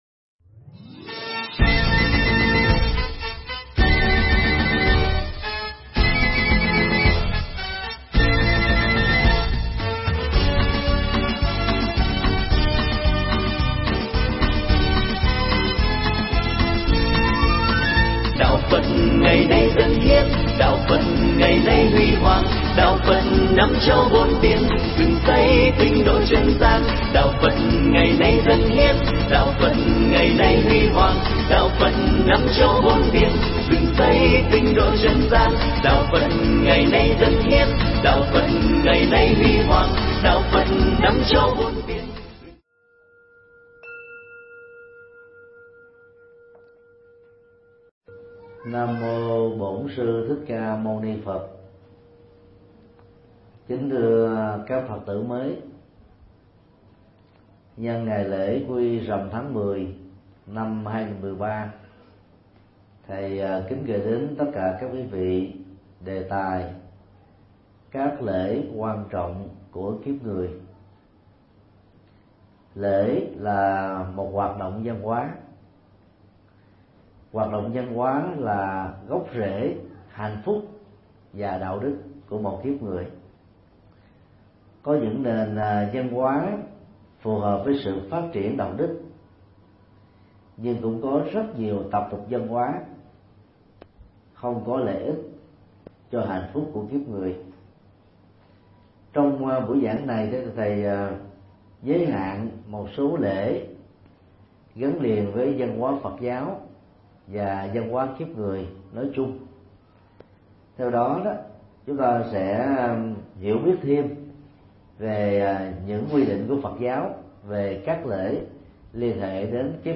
Pháp thoại
Giảng tại cơ sở sinh hoạt tạm của chùa Giác Ngộ